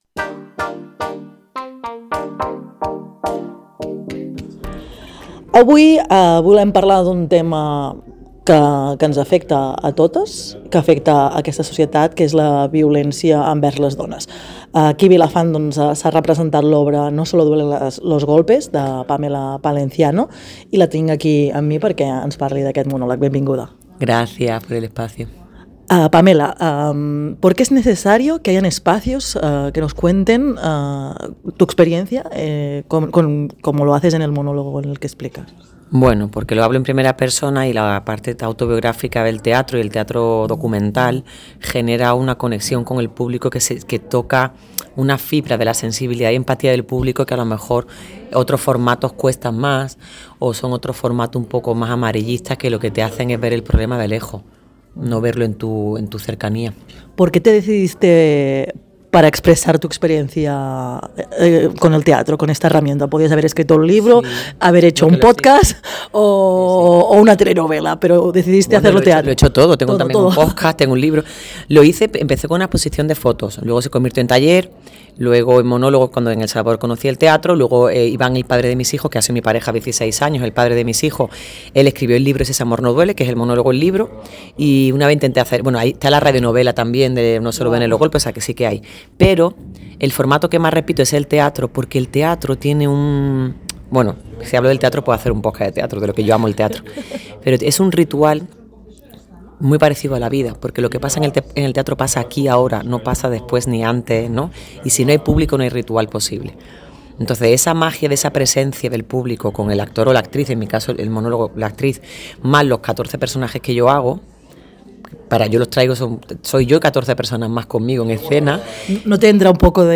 Una entrevista intensa, honesta i plena de veritat.